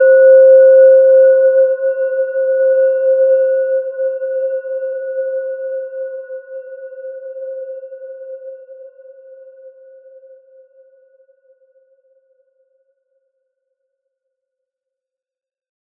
Gentle-Metallic-4-C5-mf.wav